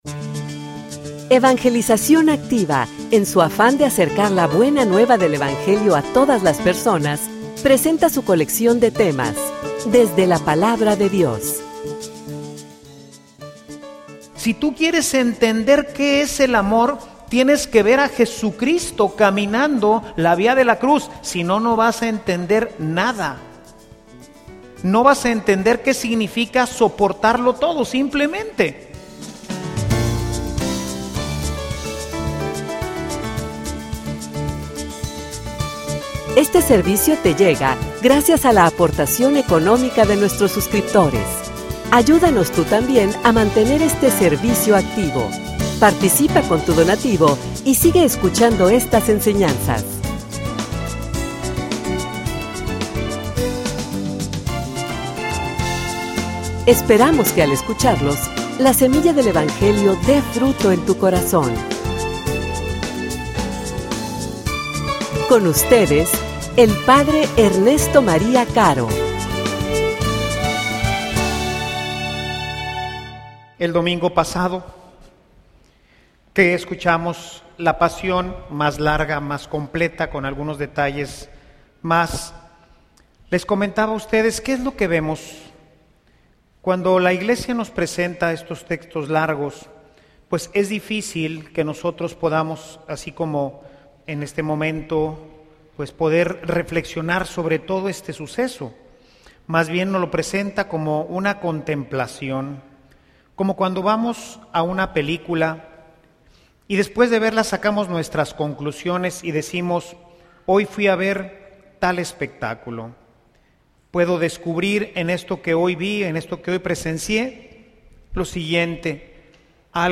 homilia_Contemplacion_sobre_el_amor.mp3